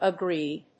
音節a・gréed 発音記号・読み方
/ʌˈgrid(米国英語), ʌˈgri:d(英国英語)/